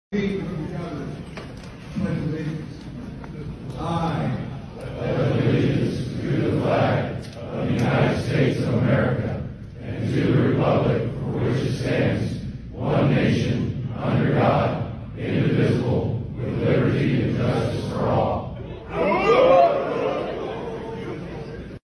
The Combat Vets Motorcycle Association let Freedom Ring with a Shotgun Blast...